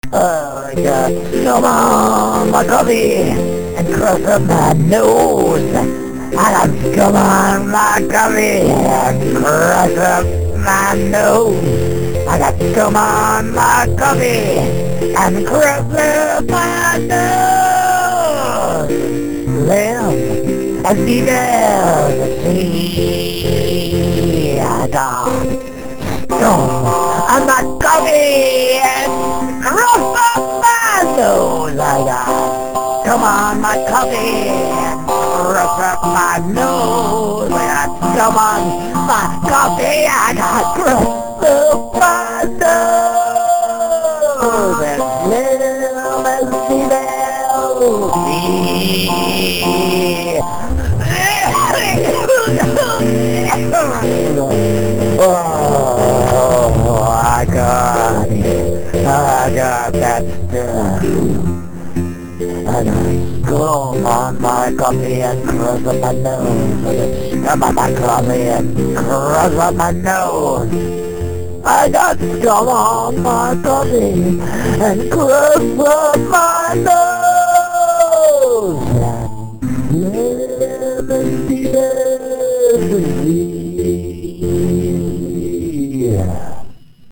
[Key of F, waltz time:]
for an audio rendition of this tune.